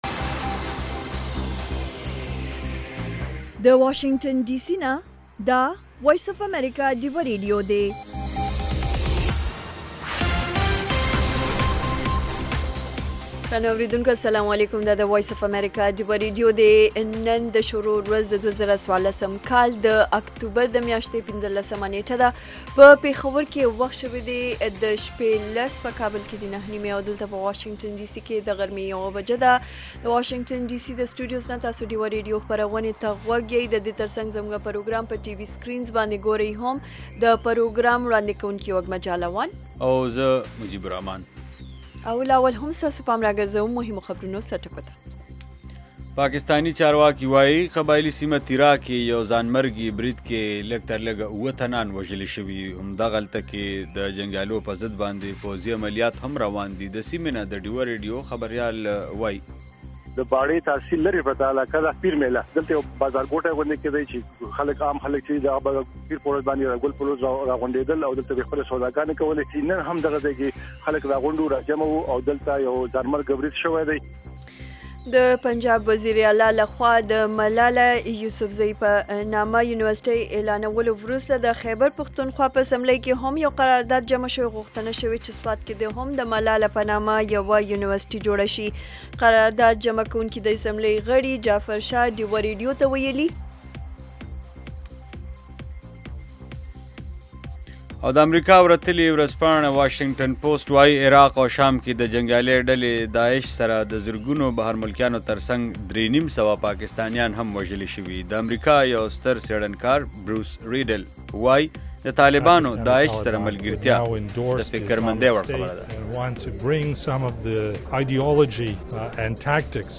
خبرونه - 1700